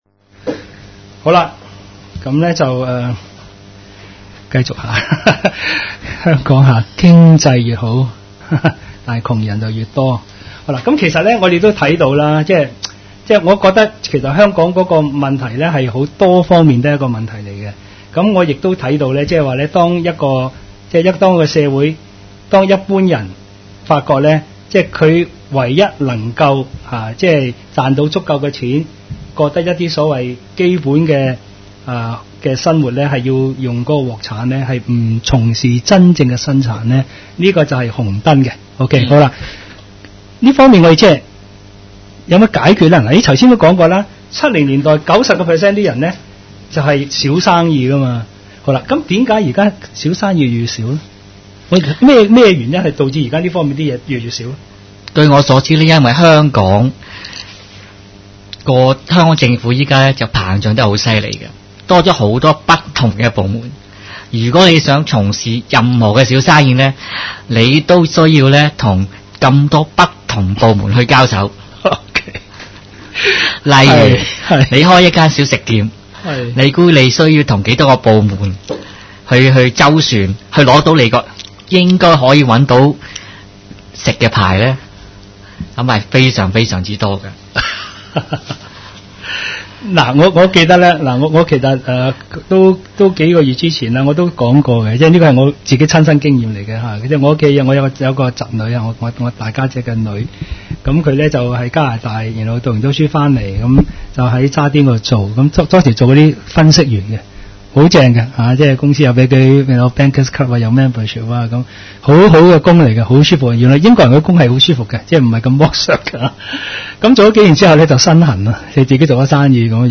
主持人 Hosted by